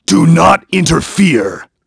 Clause_ice-Vox_Skill3_b.wav